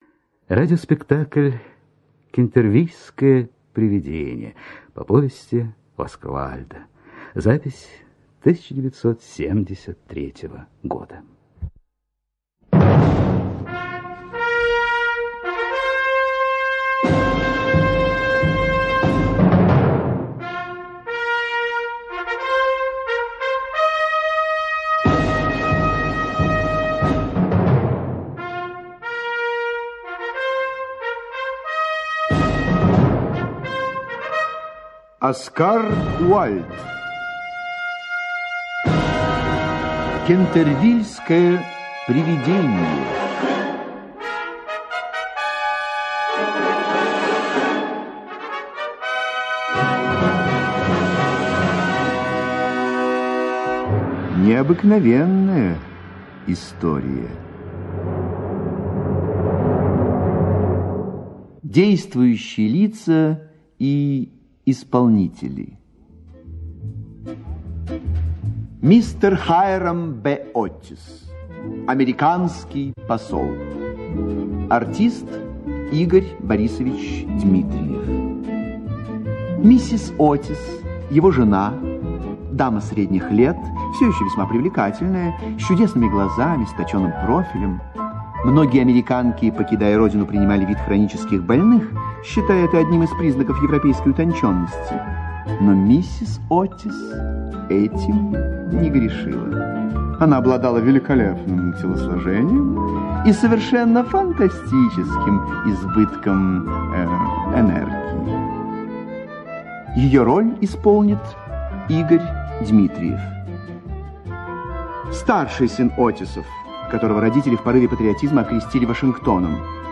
Слушайте Кентервильское привидение - аудиосказка Уайльда О. Сказка о том, как триста лет томилось приведение в одном замке...